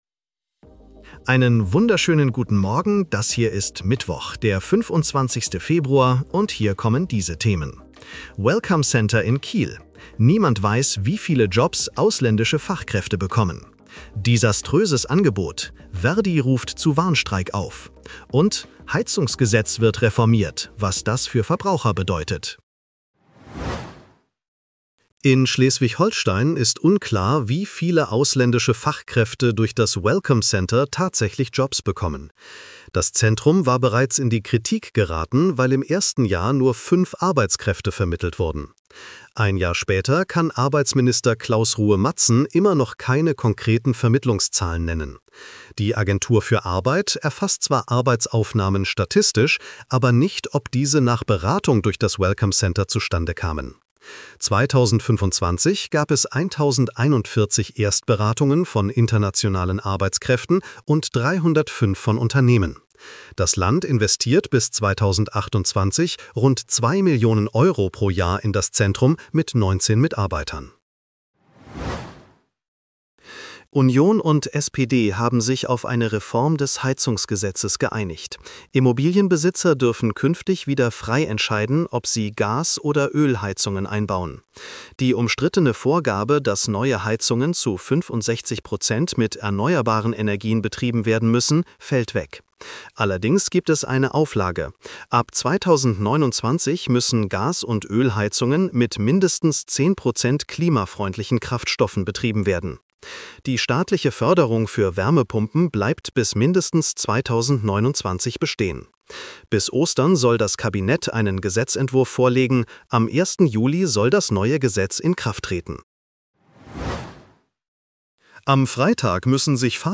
Nachrichten-Botcast bekommst Du ab 7:30 Uhr die wichtigsten Infos